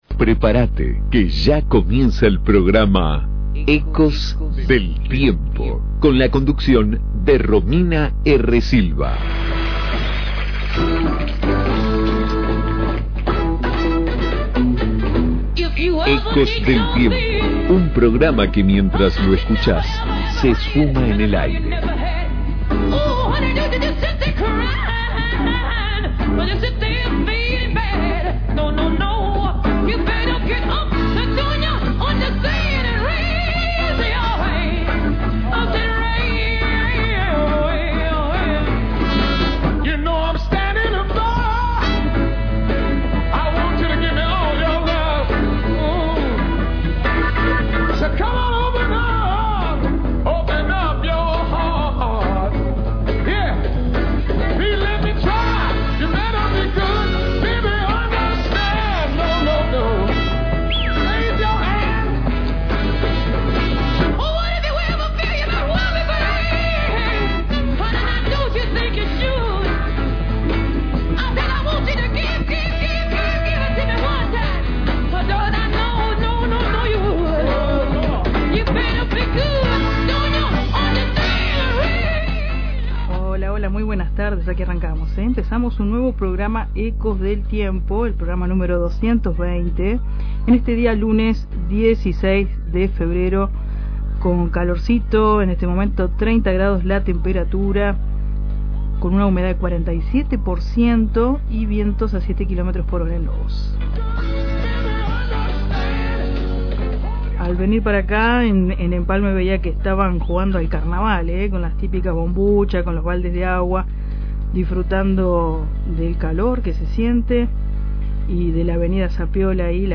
♦Todos los Lunes de 15 a 17 horas por Fm Reencuentro 102.9 ♦Seguinos en nuestra página de Face e Instagram: Programa Ecos del Tiempo.